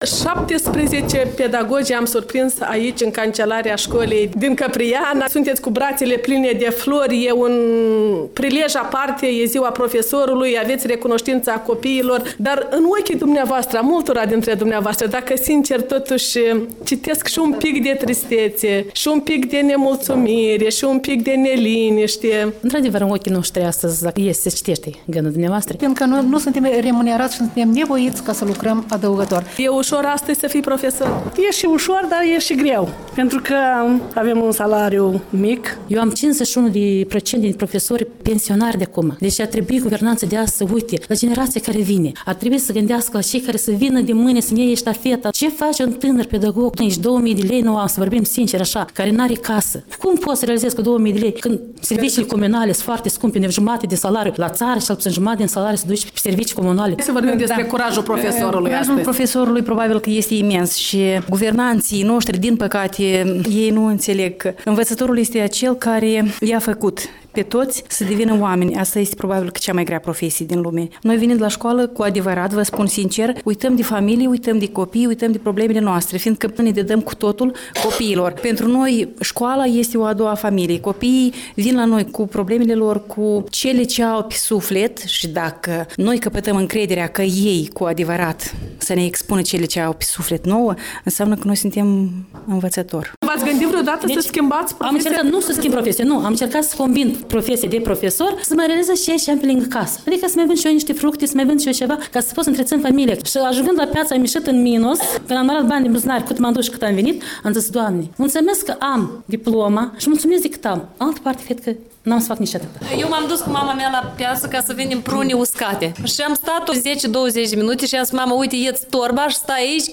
La Căpriana, Europa Liberă în dialog cu profesorii școlii locale.
De ziua profesorului, cu microfonul Europei Libere la Căpriana